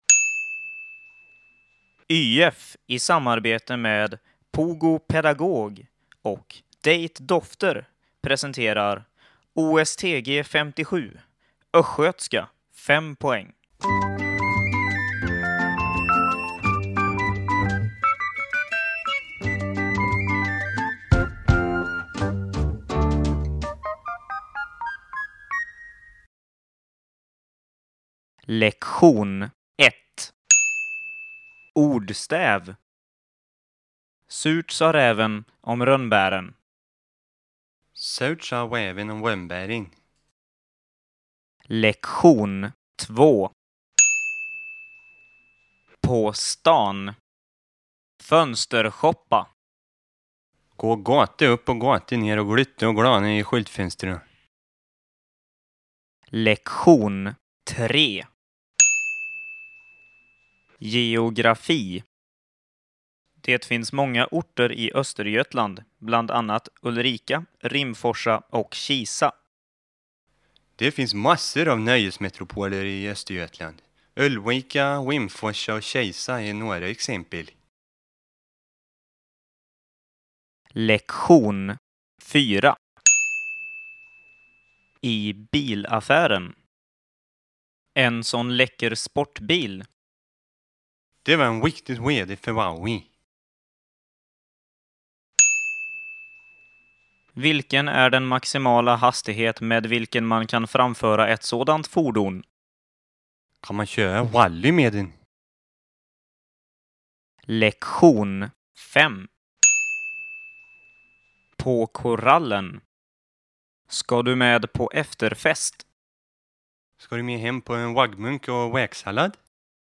Språka på Östgötska
Under tiden på Carat var detta ibland föremål för diskussioner och glada skratt – det är ju en fantastisk dialekt på alla sätt.
Lektion-i-Östgötska.mp3